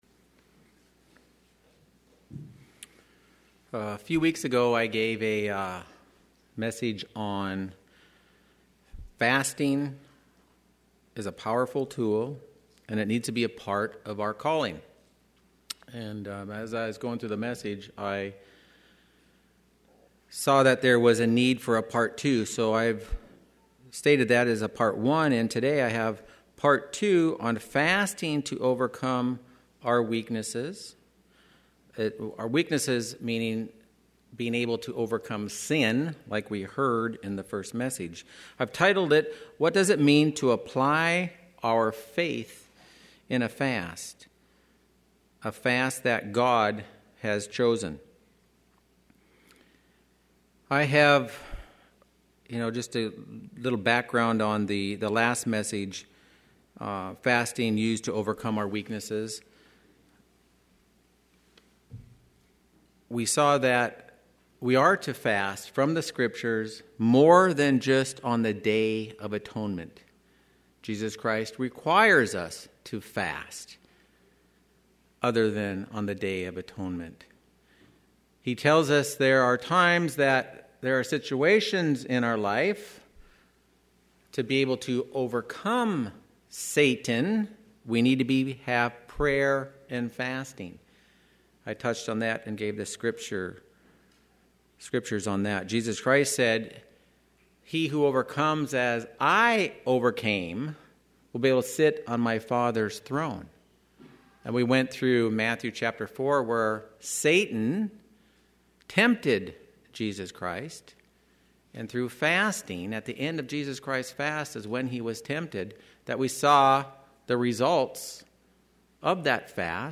Part II Split-Sermon Given on Nov 24